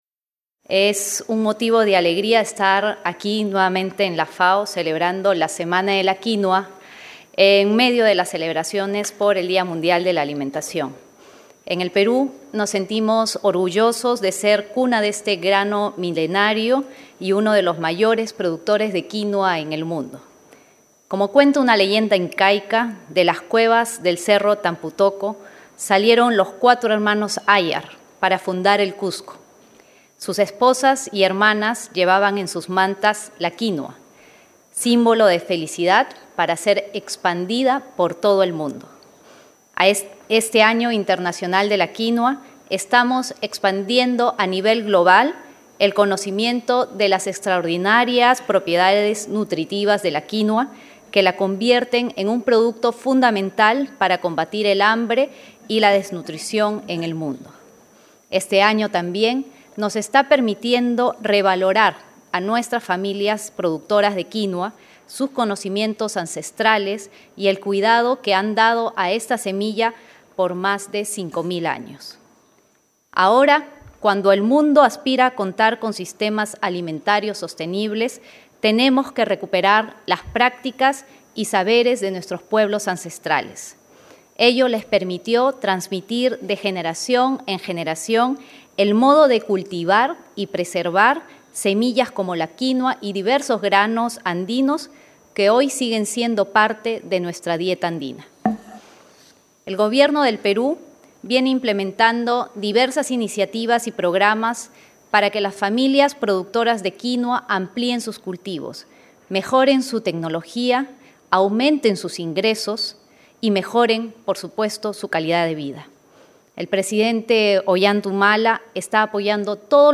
17 de octubre 2013, Roma – Se marcó el fin del Año Internacional de la Quinua con una ceremonia conclusiva en la sede central de la FAO, pero no se deben olvidar los mensajes de este año.
Alocución de la Excma. Sra. Doña Nadine Heredia Alarcón de Humala, orador principal, Embajadora Especial de la FAO para el Año Internacional de la Quinua y Primera Dama de Perú. (Celebración de la Quinua y cosecha simbólica)